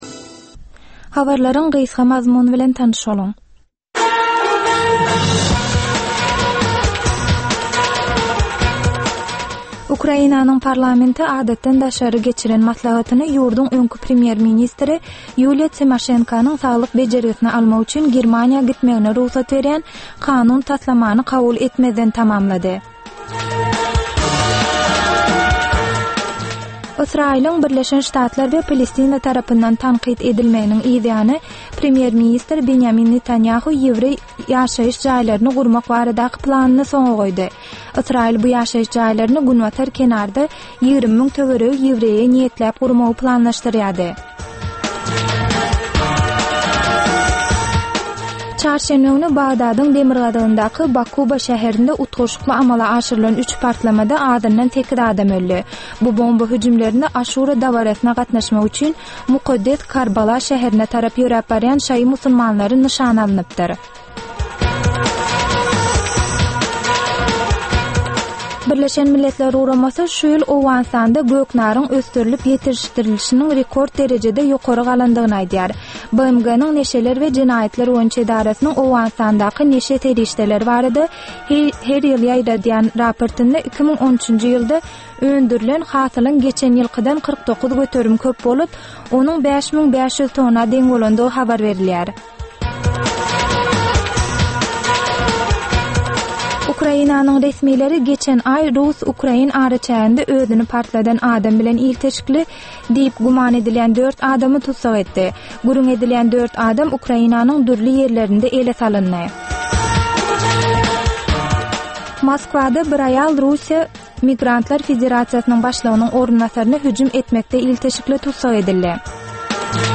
Türkmenistanly ýaşlaryň durmuş, okuw, iş meselelerini beýan etmek üçin döredilen programma. Bu programmada ýaşlary gyzyklandyrýan, ynjalykdan gaçyrýan zatlar hakda aç-açan we janly gürrüň edilýär.